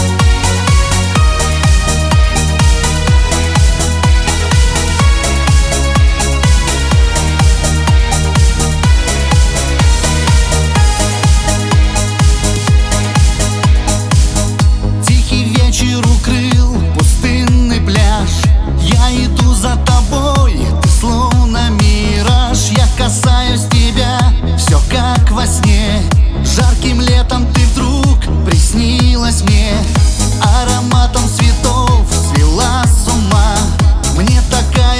Chanson in Russian